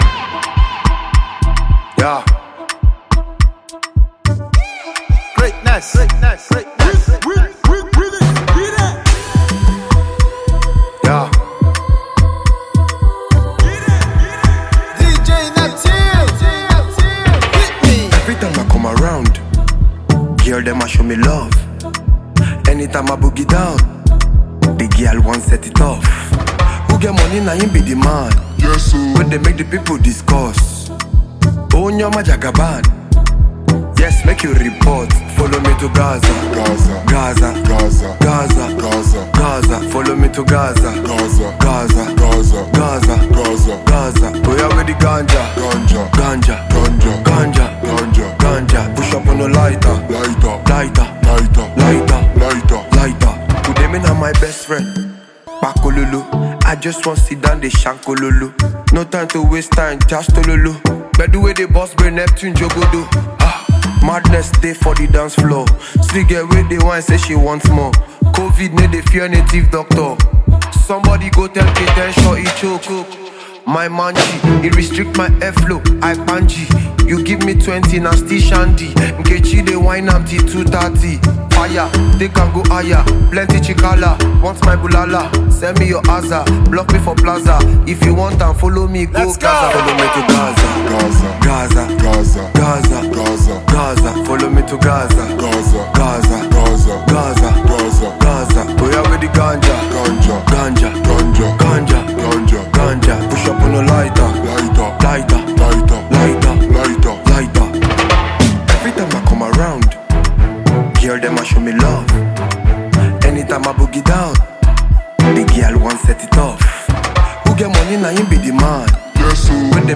Nigerian Dancehall singer